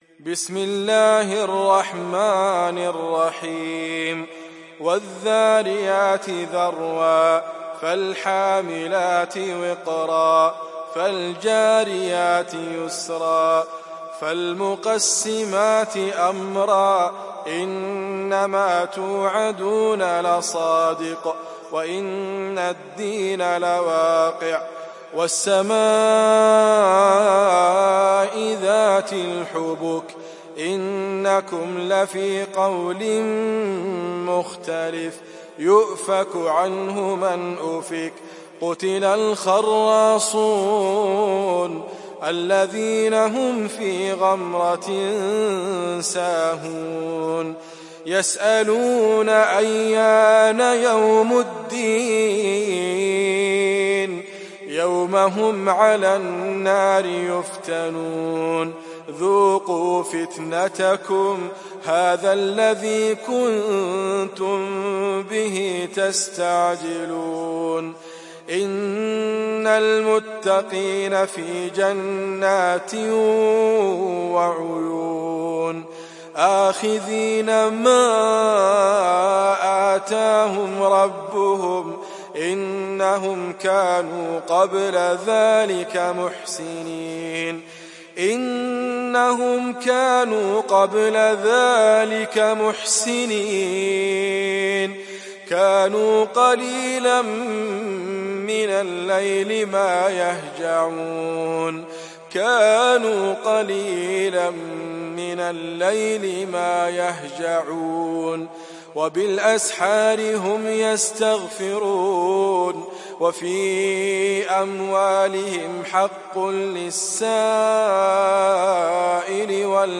Surat Ad Dariyat Download mp3 Idriss Abkar Riwayat Hafs dari Asim, Download Quran dan mendengarkan mp3 tautan langsung penuh